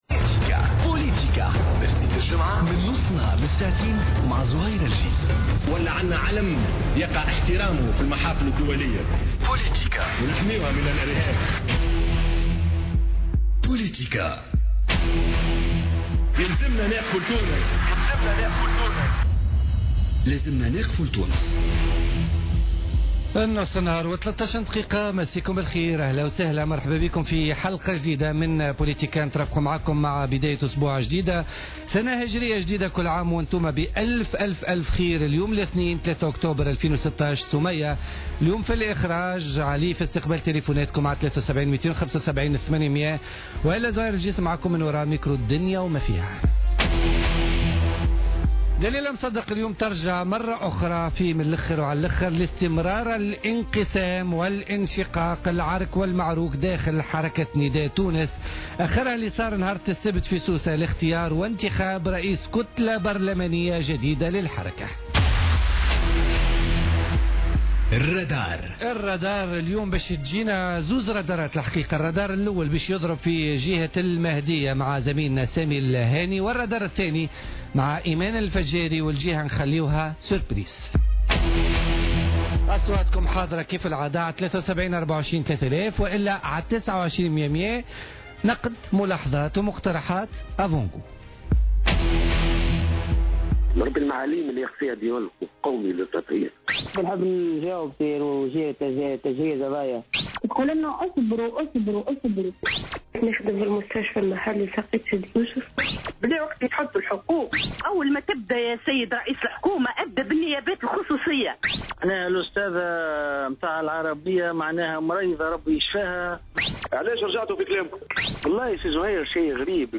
La députée Hajer Ben Cheikh Et Boujomaa Rmili , invités de Politica